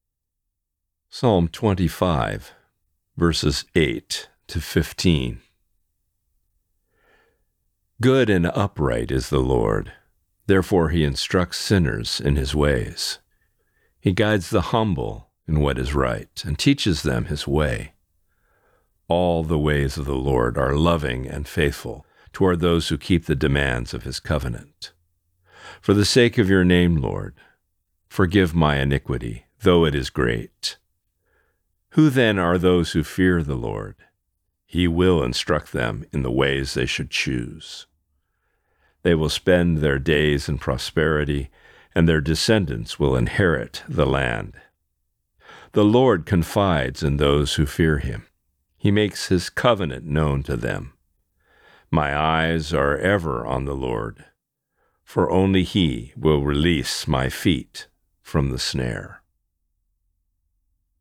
Reading: Psalm 25:8-15